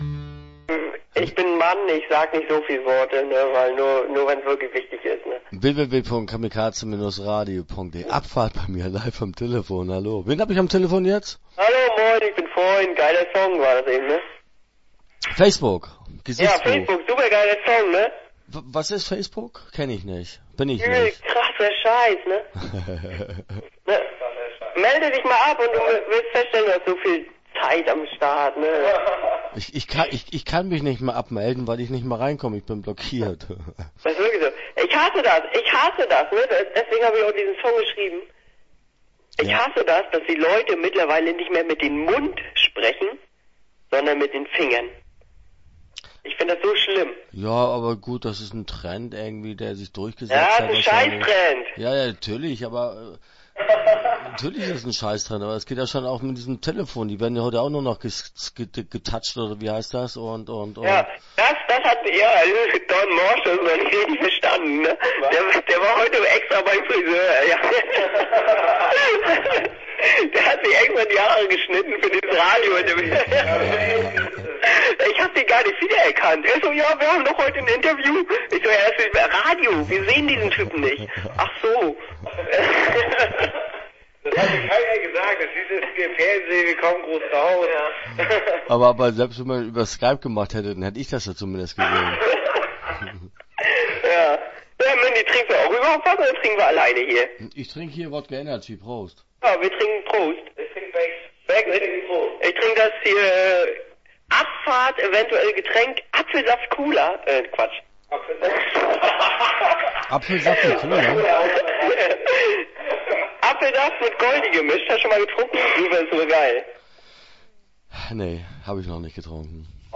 Abfahrt - Interview Teil 1 (11:38)